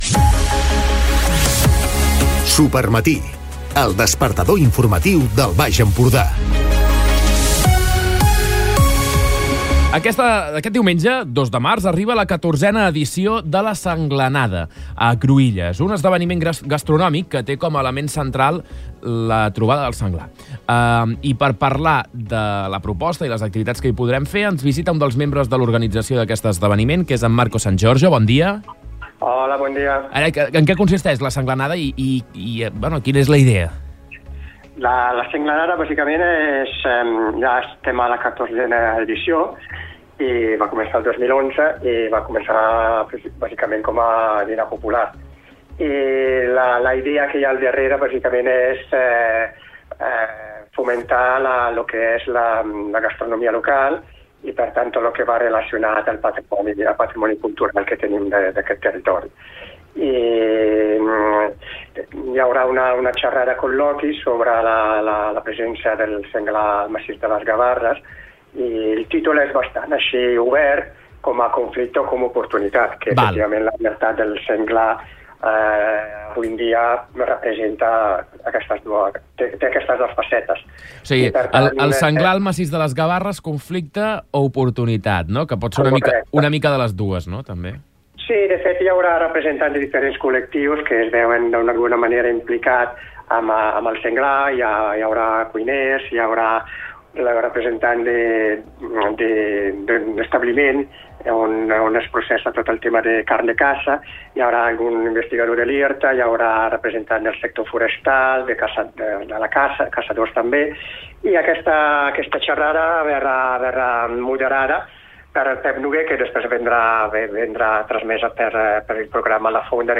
Entrevistes Supermatí